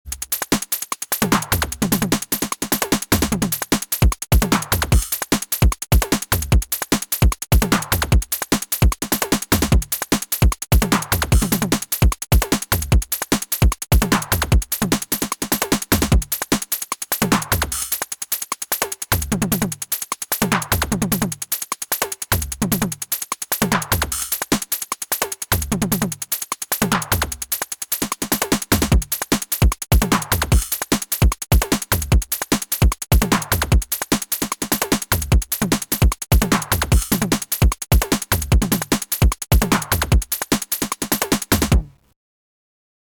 I don’t know what a Machinedrum is supposed to sound like but I imagine it being something like this :smiley: Syntakt through NI Bite (12 bit and a bit of crunch) and Supercharger.